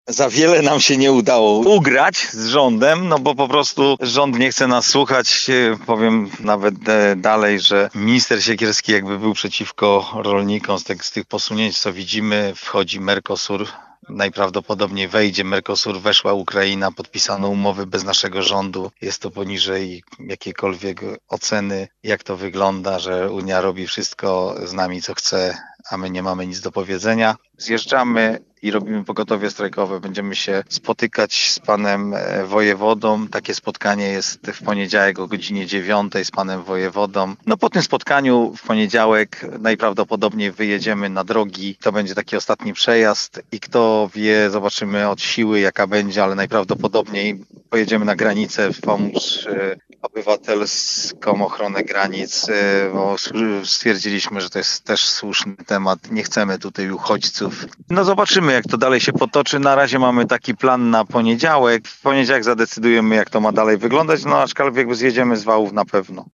przedstawiciel protestujących rolników.